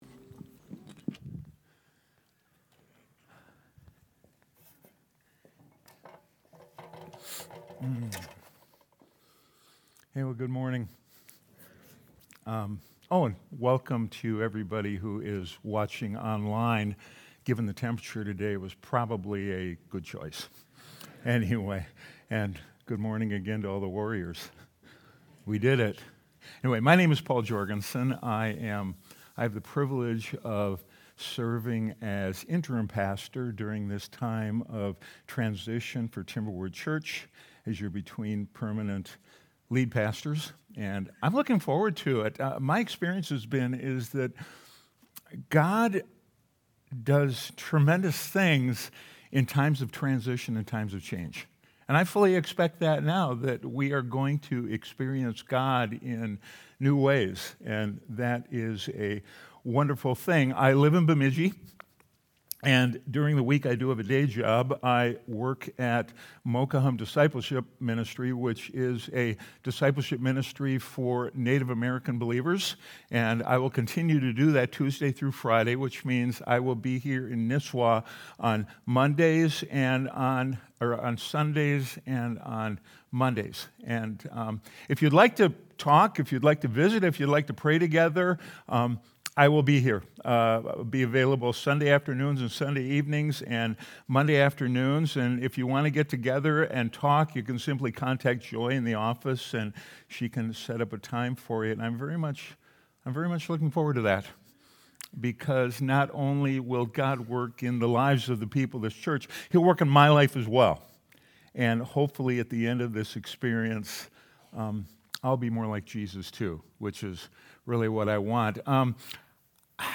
Sunday Sermon: 12-14-25